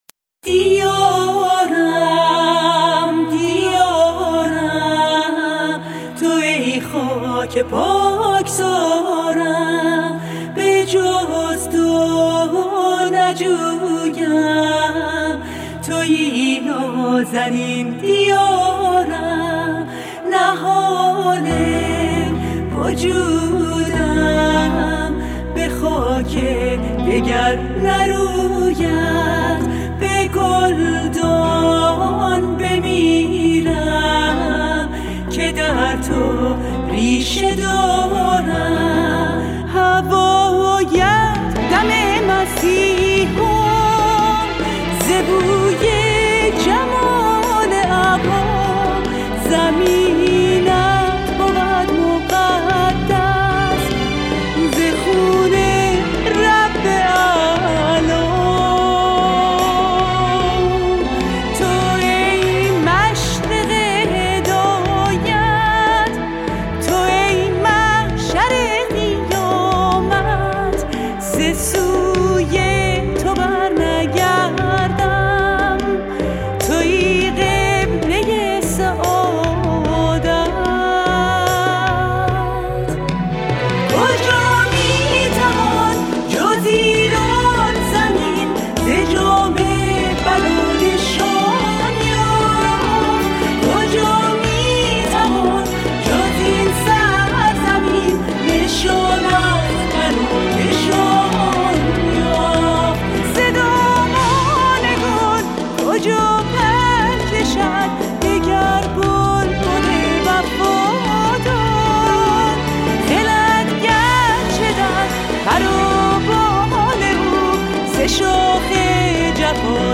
سرود - شماره 6 | تعالیم و عقاید آئین بهائی